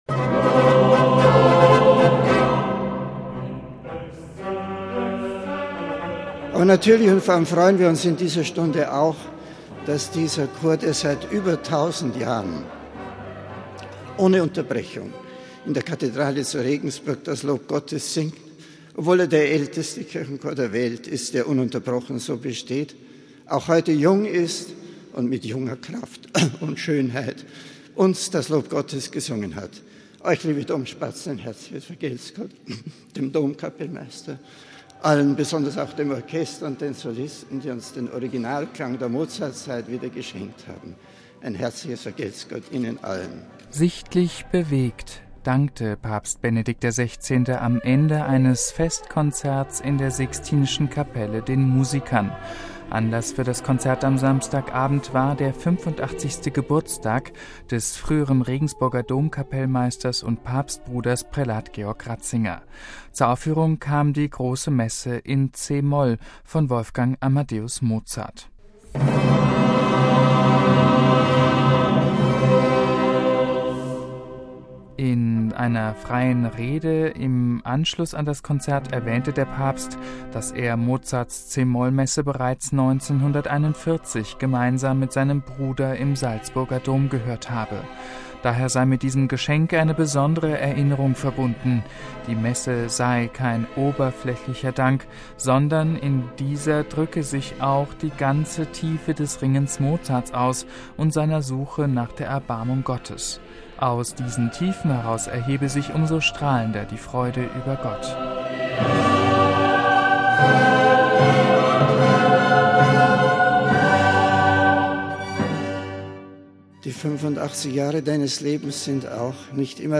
Sichtlich bewegt dankte Papst Benedikt XVI. am Ende eines Festkonzerts in der Sixtinischen Kapelle den Musikern.